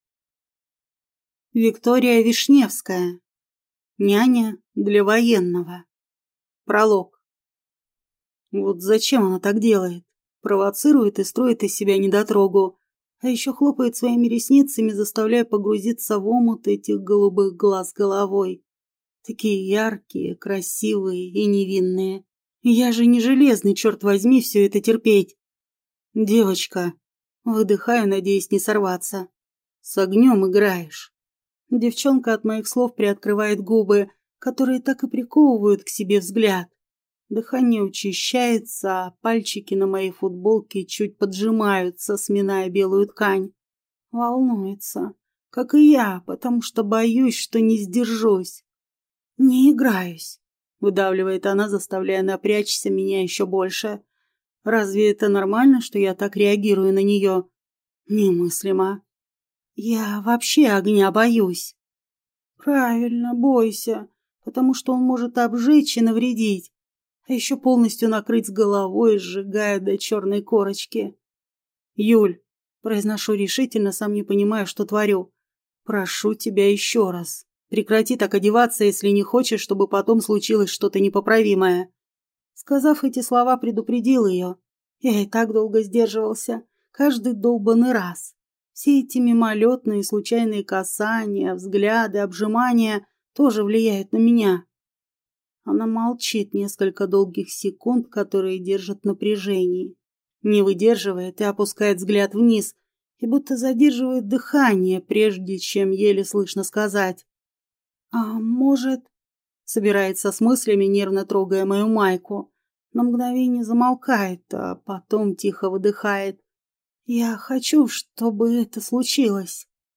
Генерал черных драконов. Книга 1. Попаданка с довеском для Хозяина Запределья (слушать аудиокнигу бесплатно) - автор Любовь Черникова